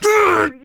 B_pain3.ogg